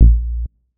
Bass (7).wav